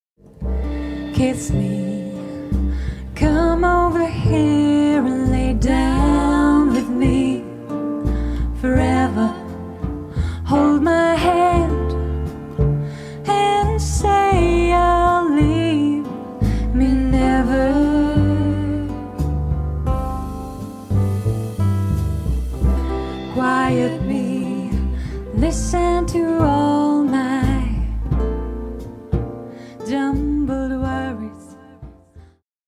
An original Bossa Nova composition